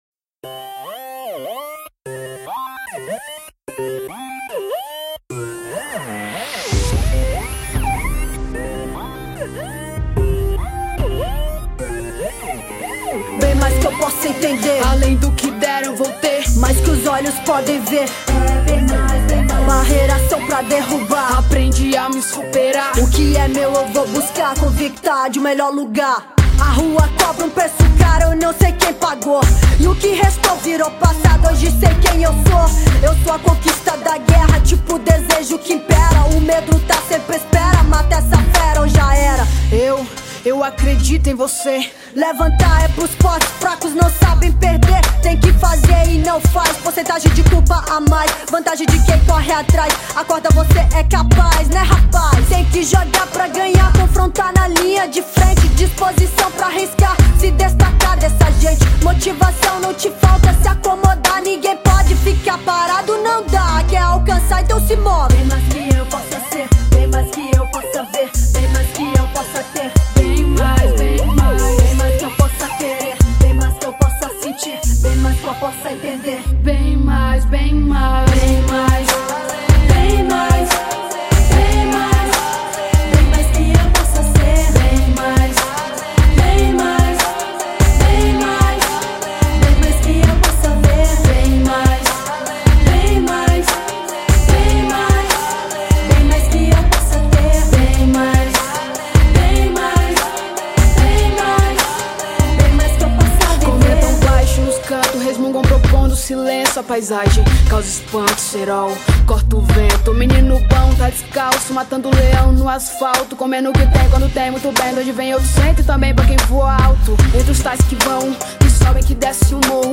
rap gospel.